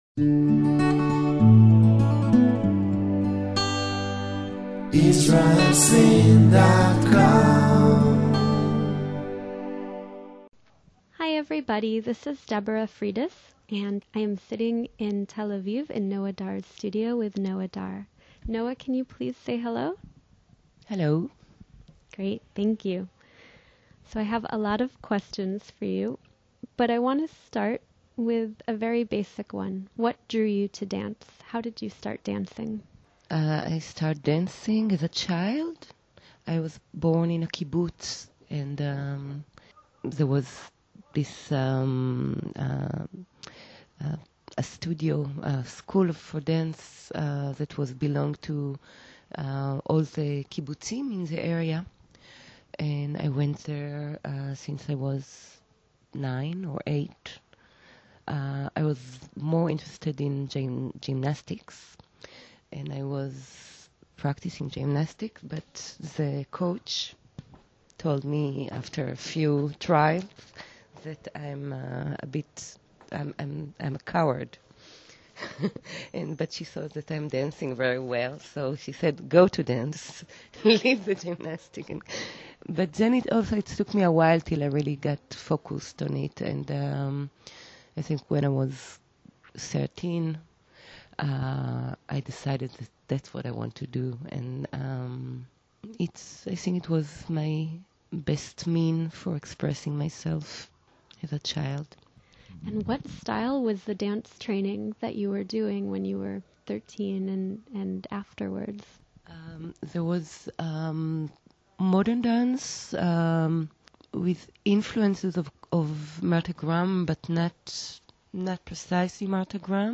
We recently sat down at her studio in Tel Aviv to discuss her rich career path, the development of her movement language and creative process, and the relationship of her work to Israeli society and culture.